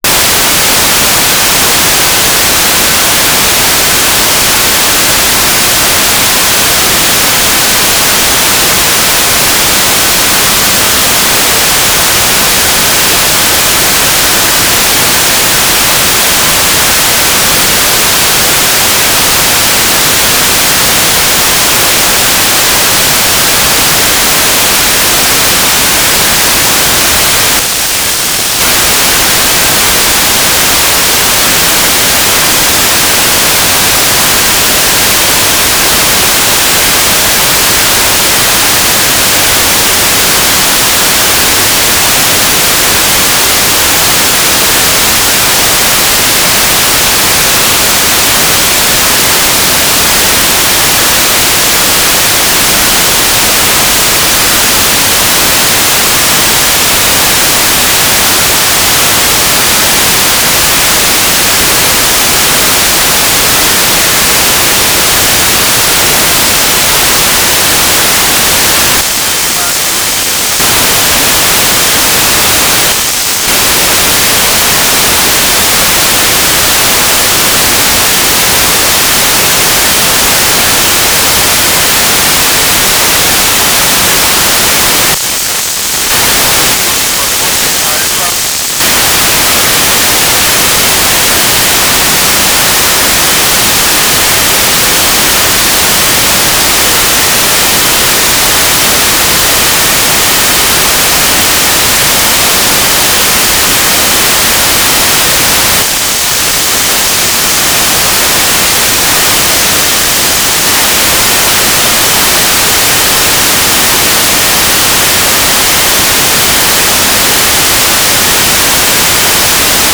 "transmitter_description": "Mode V/U FM - Voice Repeater CTCSS 67.0 Hz",
"transmitter_mode": "FM",